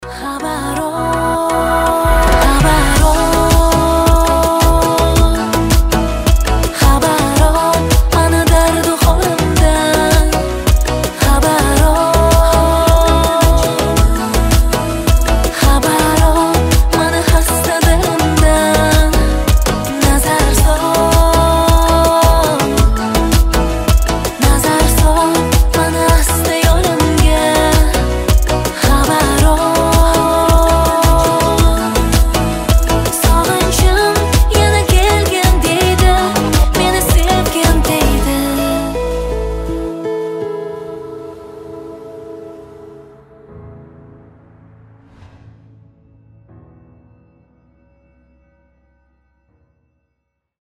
• Качество: 320, Stereo
красивый женский голос
узбекские
Красивый романтический рингтон